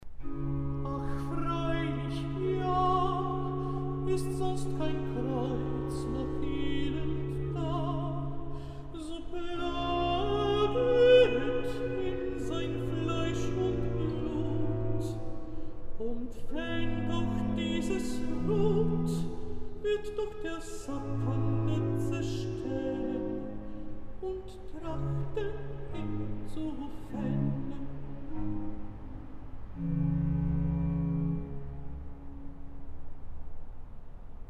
Kantate